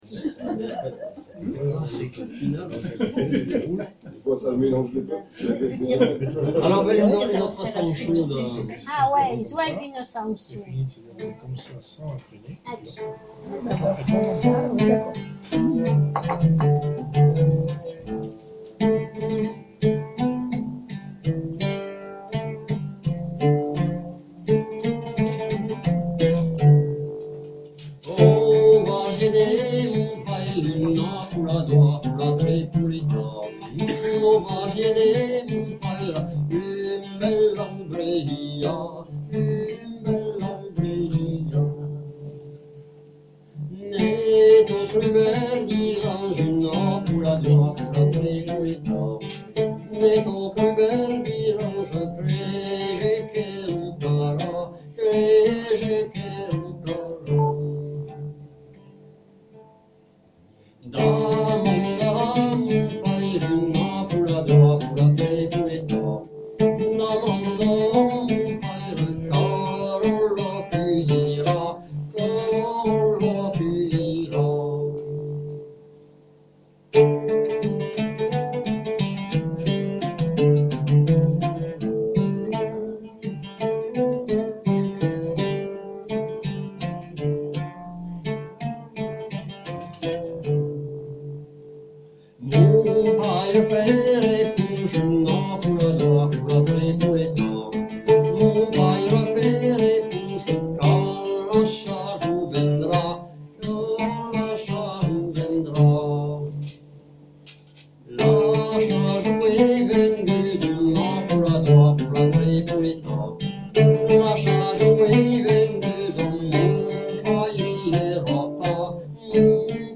se réunit tous les deux ou trois mois dans un café de TULLE
Peu d'enregistrements de qualité
car le lieu est très bruyant !